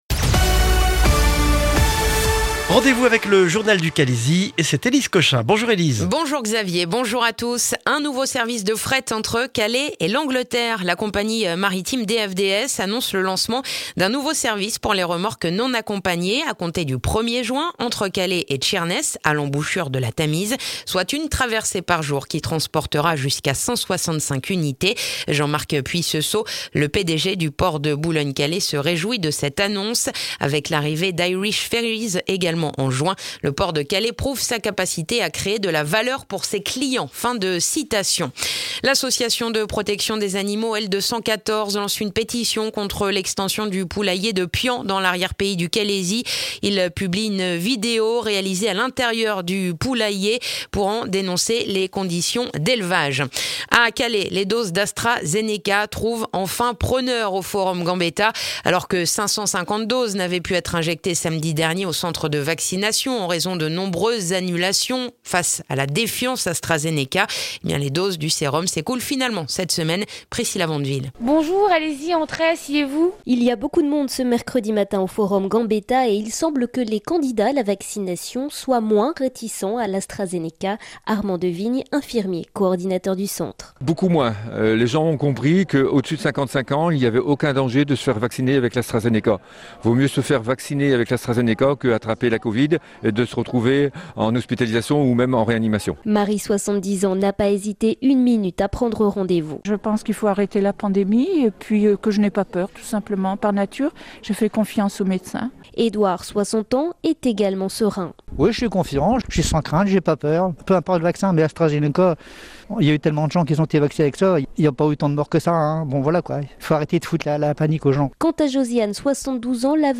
Le journal du jeudi 8 avril dans le calaisis